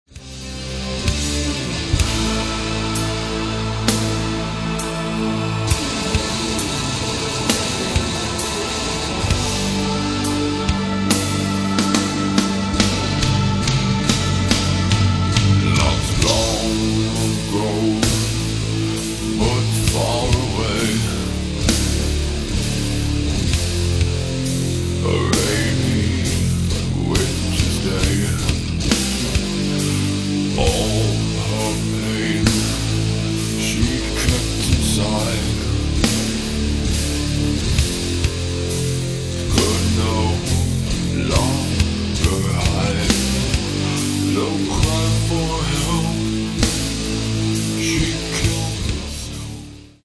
depressing goth sounds
has a more dark, aggresive sound